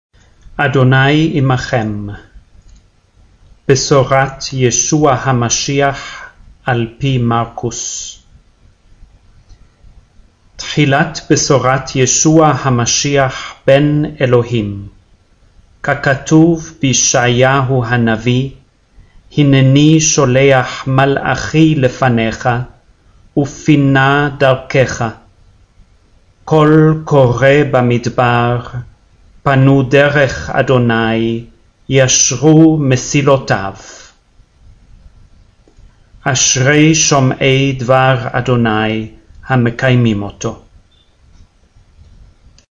04- Gospel Reading- Mark.mp3